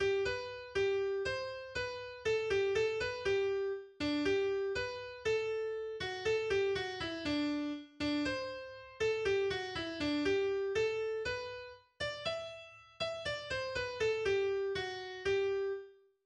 Weihnachtslied